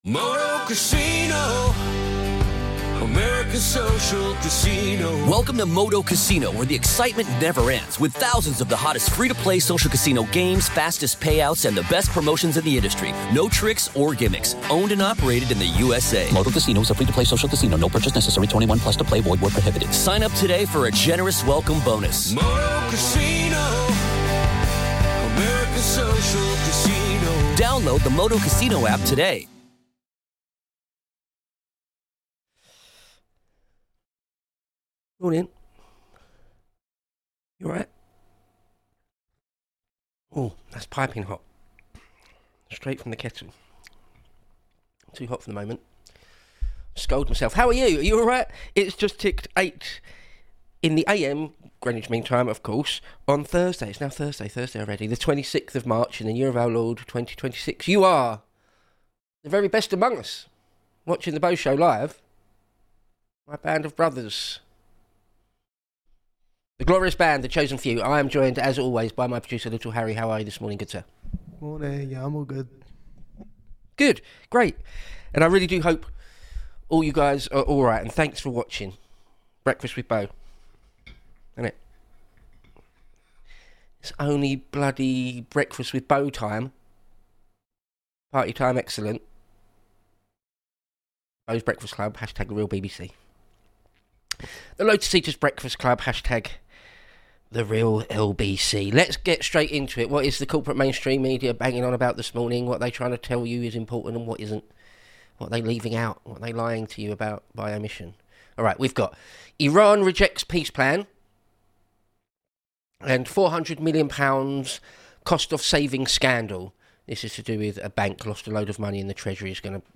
Live 8-9am GMT on weekdays.